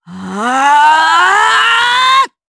Xerah-Vox_Casting2_Madness_jp.wav